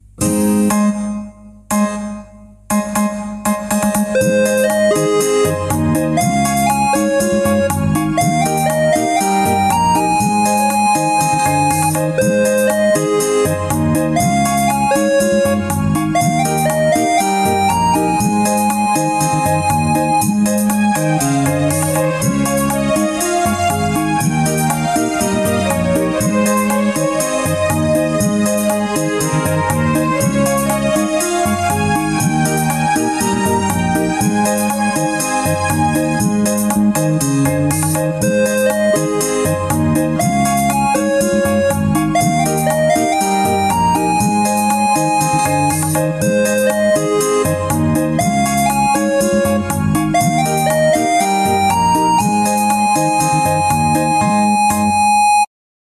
Genre: Nada dering panggilan
Suaranya unik, lucu, dan pastinya beda dari yang lain.